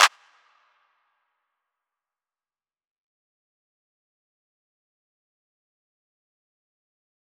DMV3_Clap 17.wav